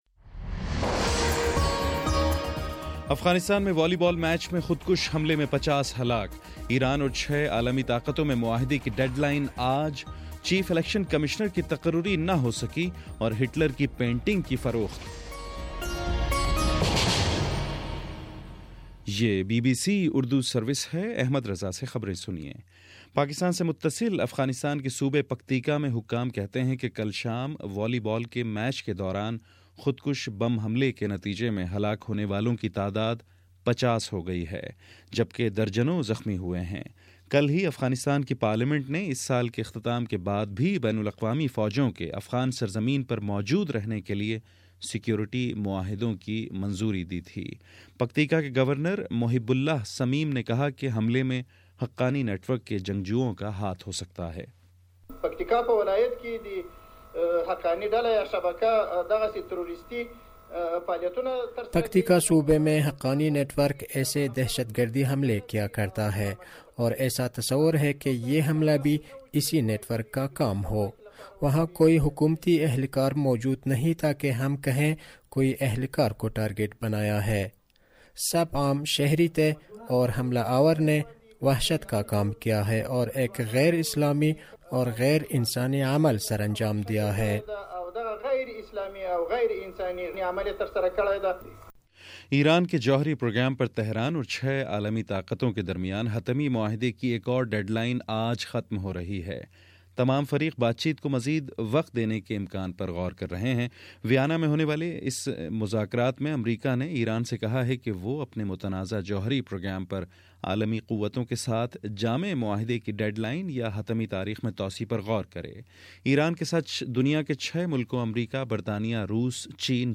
نومبر24: صبح نو بجے کا نیوز بُلیٹن
دس منٹ کا نیوز بُلیٹن روزانہ پاکستانی وقت کے مطابق صبح 9 بجے، شام 6 بجے اور پھر 7 بجے۔